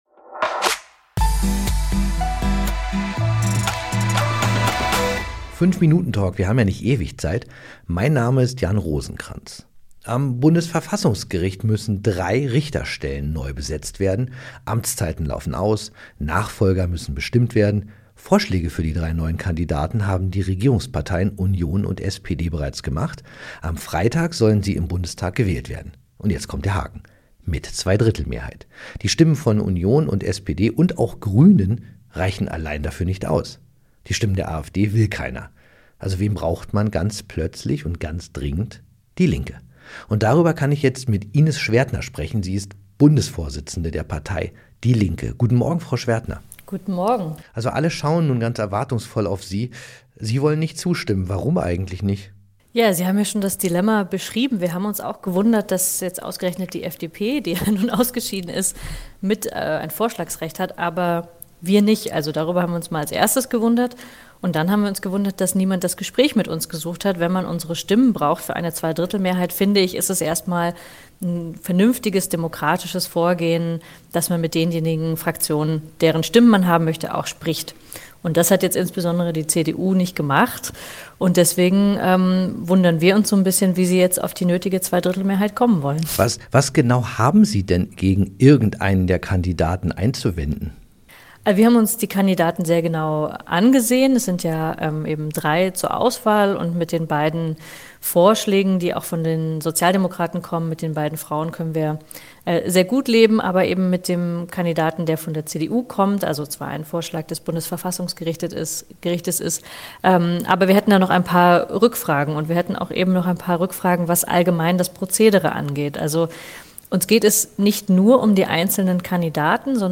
Darüber spricht Linken-Chefin Ines Schwerdtner mit